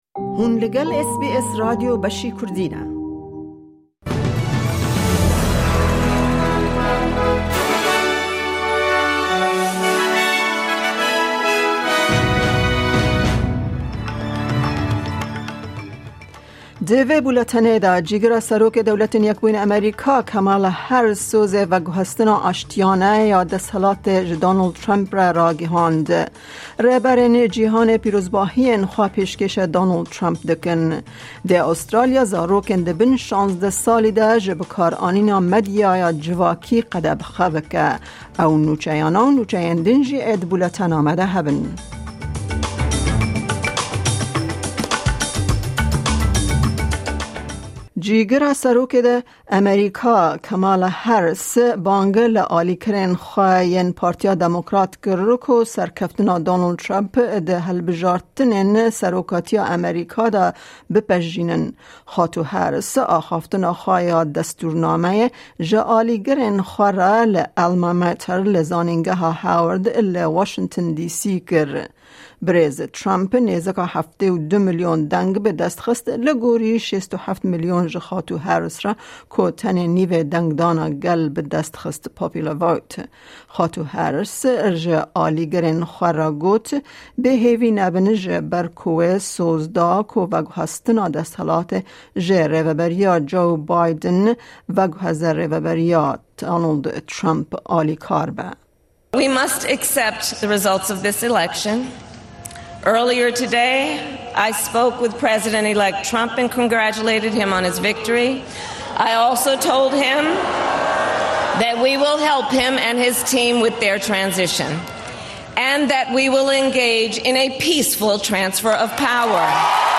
Download - Nûçeyên roja Pêncşemê 18î Nîsana 2024 | Podbean